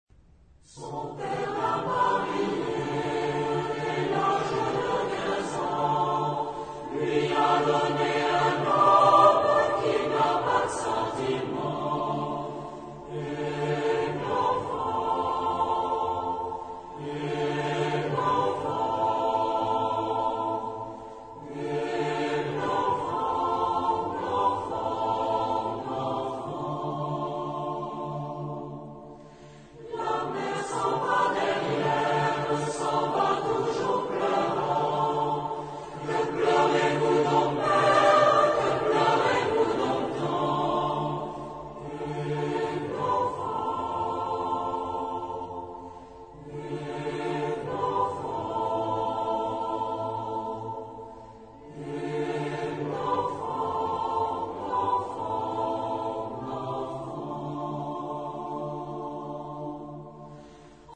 Genre-Stil-Form: weltlich ; Volkstümlich
Chorgattung: SSA  (3 Frauenchor Stimmen )
Solisten: Sopranos (2)  (2 Solist(en))
Tonart(en): G-Dur